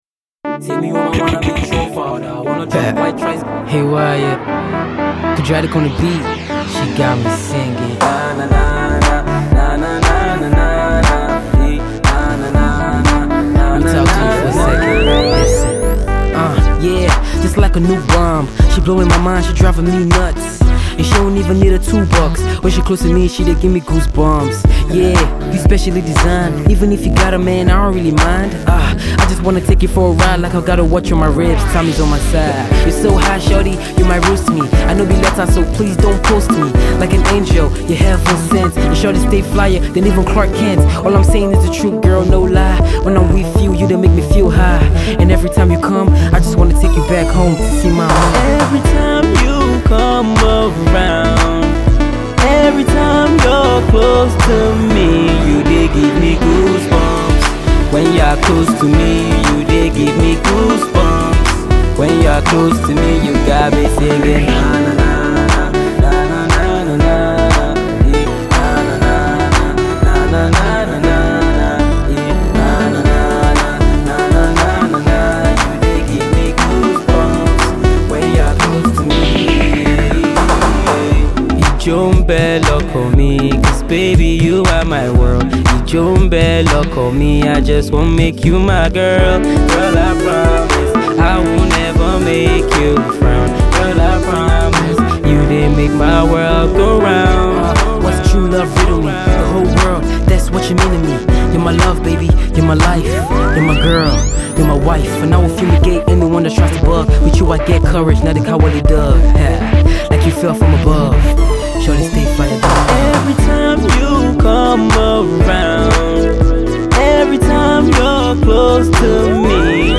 Afro Pop and Hip-Hop fusion track